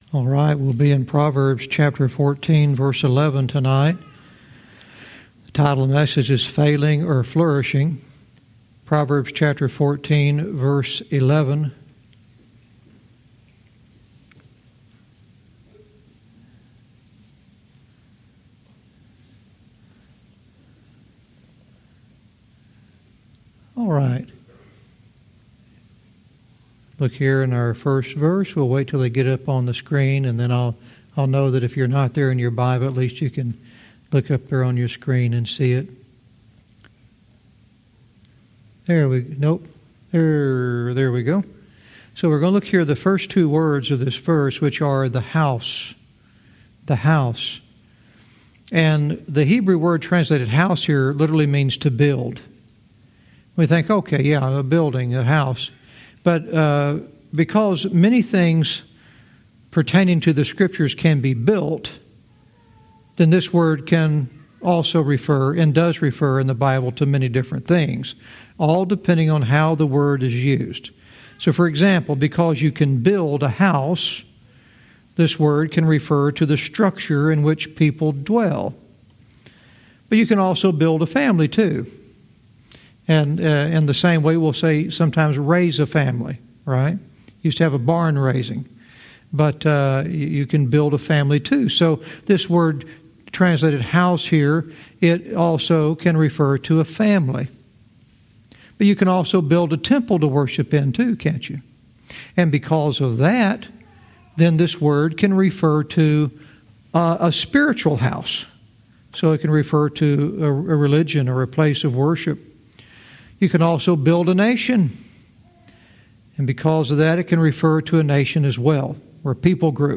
Verse by verse teaching - Proverbs 14:11 "Failing or Flourishing"